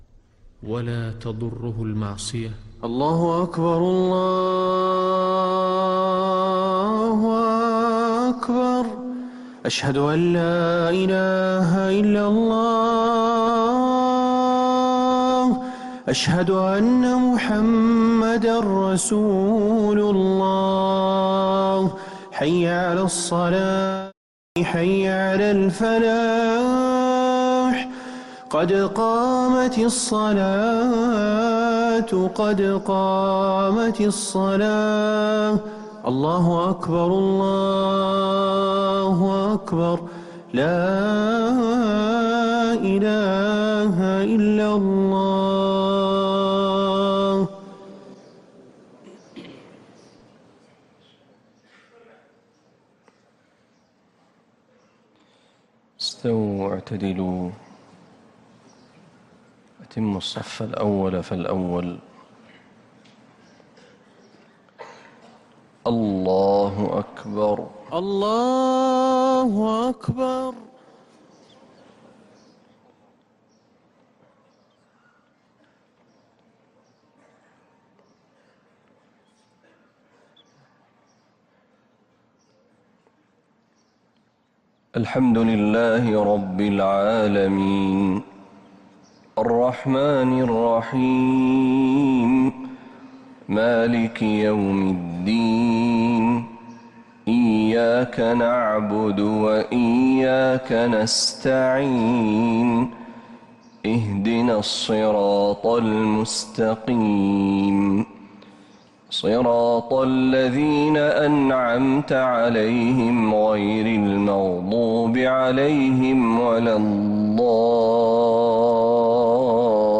Haramain Salaah Recordings: Madeenah Fajr - 27th March 2026
Madeenah Fajr - 27th March 2026